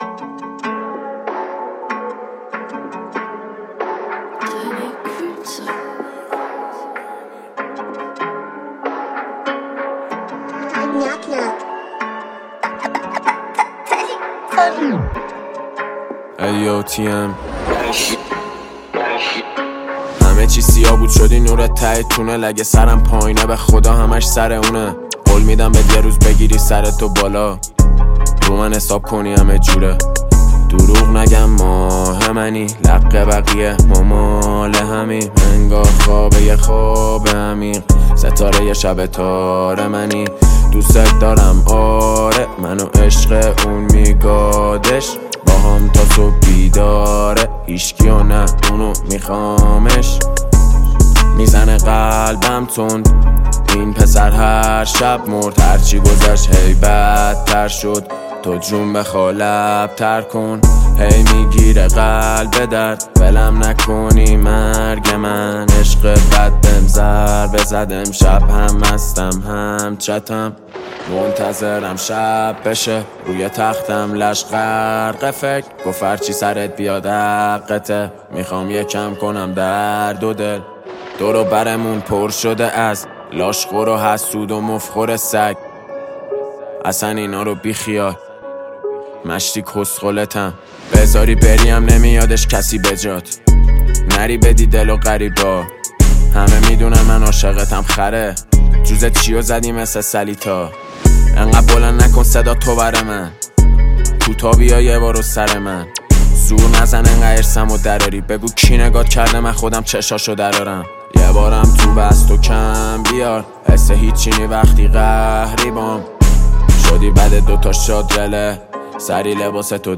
ملودی گیرا
موسیقی رپ فارسی